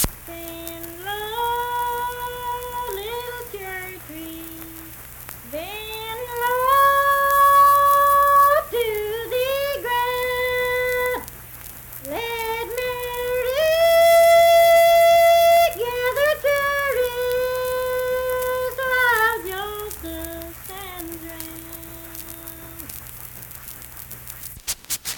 Unaccompanied vocal music
Hymns and Spiritual Music
Voice (sung)